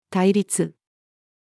対立-female.mp3